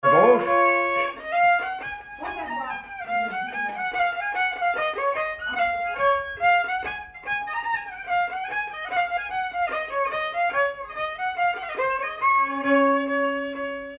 Mazurka partie 1
danse : mazurka
circonstance : bal, dancerie
Pièce musicale inédite